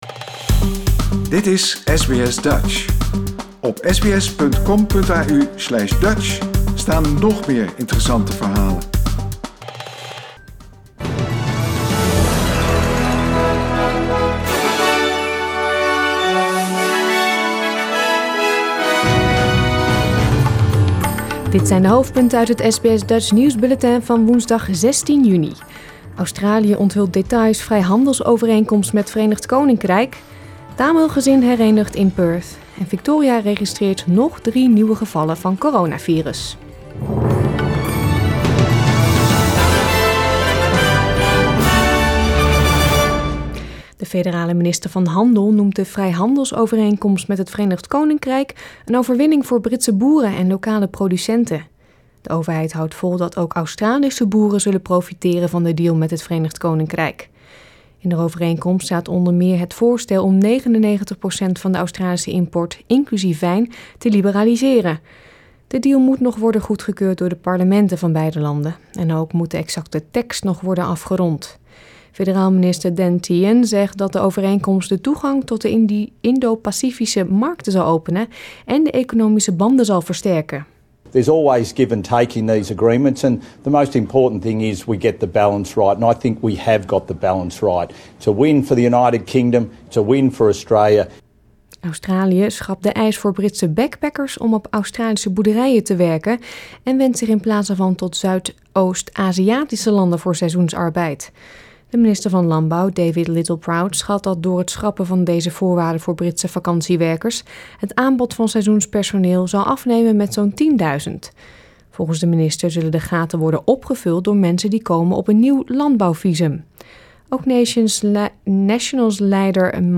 Nederlands/Australisch SBS Dutch nieuwsbulletin van woensdag 16 juni 2021